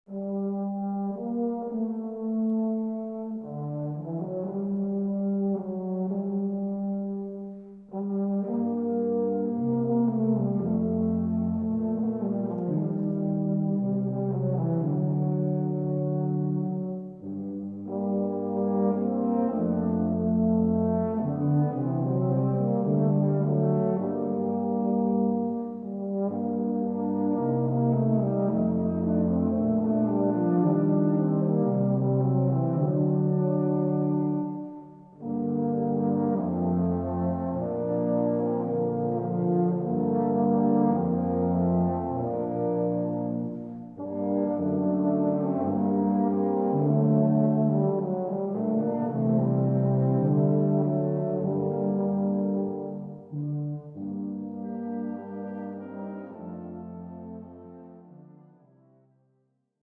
Gattung: Feierliche Musik
Besetzung: Blasorchester